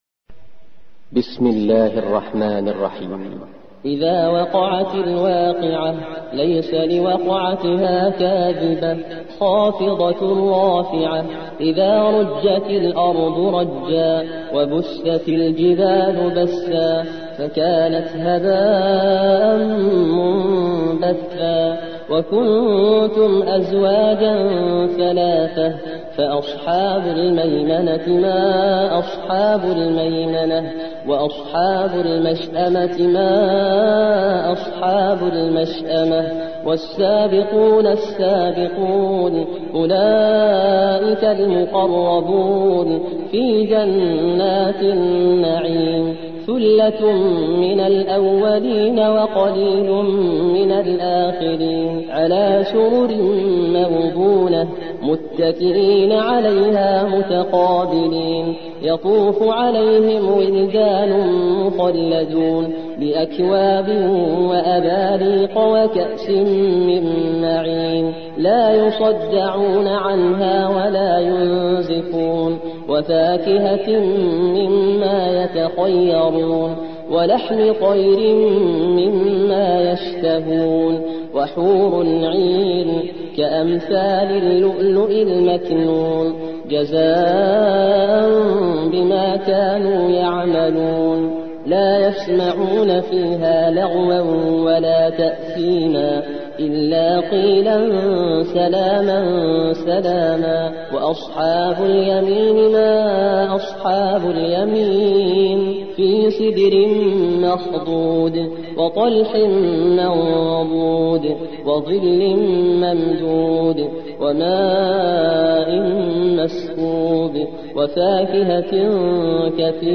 56. سورة الواقعة / القارئ